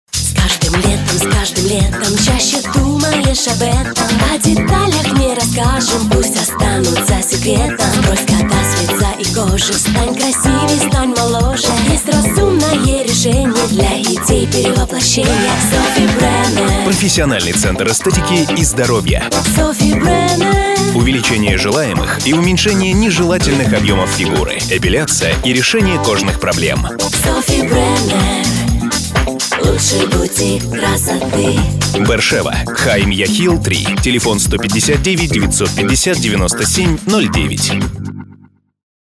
Портфолио | Радио реклама | Аудио реклама | Радио ролик | Аудио ролик
>>  вокальные ролики